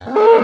cow_hurt1.ogg